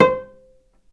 vc_pz-C5-ff.AIF